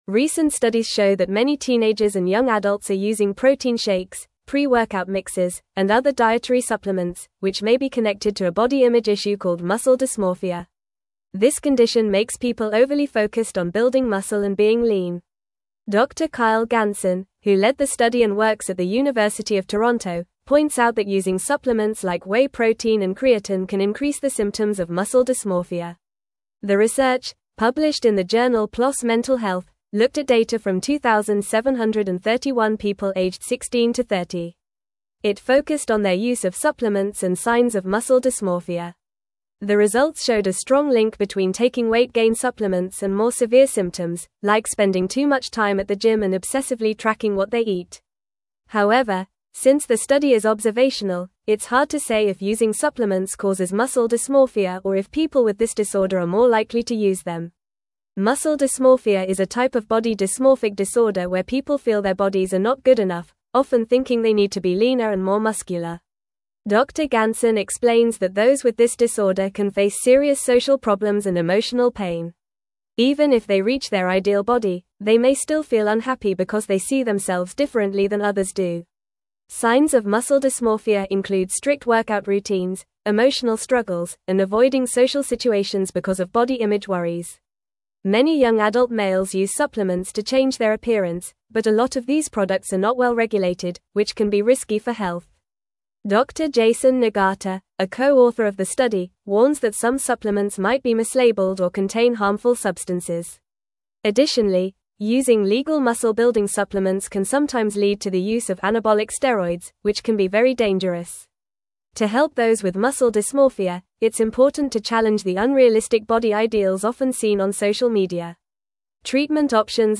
Fast
English-Newsroom-Upper-Intermediate-FAST-Reading-Link-Between-Supplements-and-Muscle-Dysmorphia-in-Youth.mp3